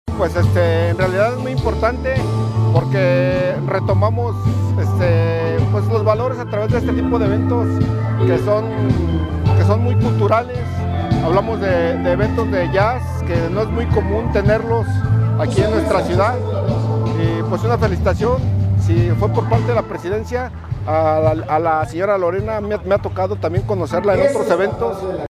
asistente del evento